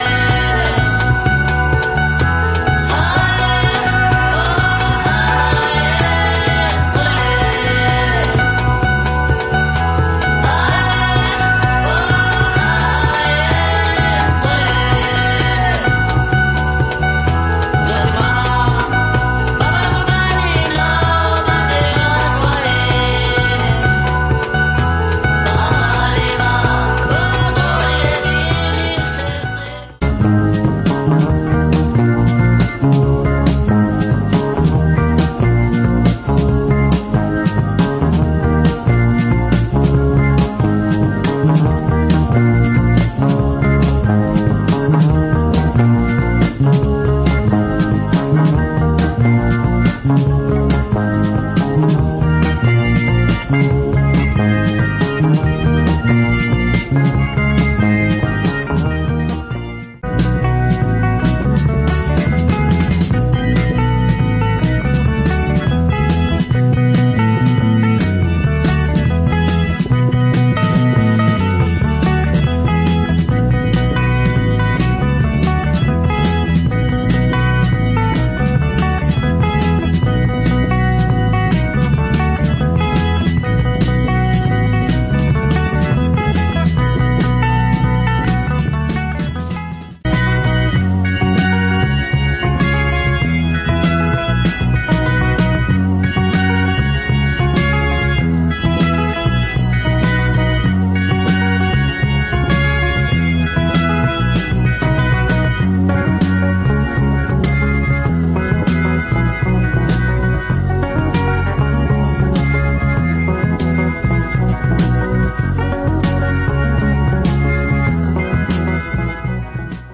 Reissue of rare afro-psychedelic LP from South Africa.
a cool mixture of incipient afro-funk and garage pop/psych